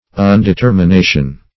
Undetermination \Un`de*ter`mi*na"tion\, n.